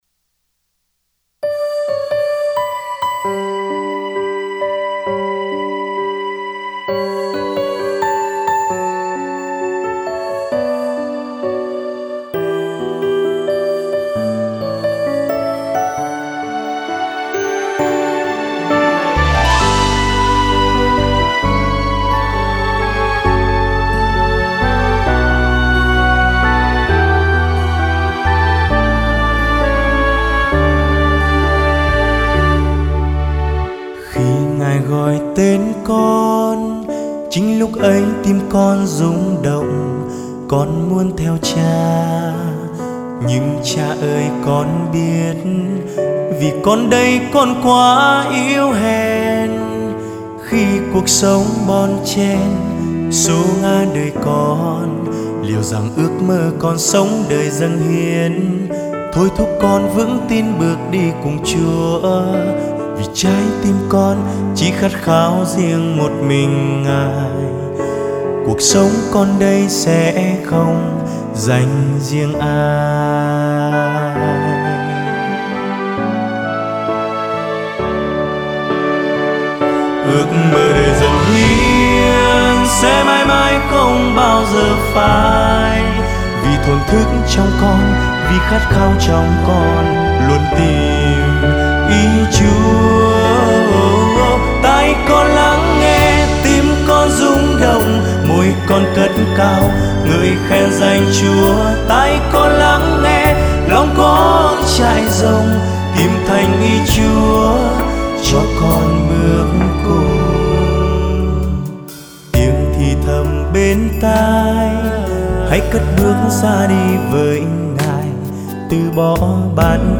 Nghe nhạc thánh ca. Bài hát được phát từ Website